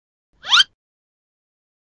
Cartoon Slip Sound Button - Free Download & Play
The Cartoon Slip is a popular audio clip perfect for your soundboard, content creation, and entertainment.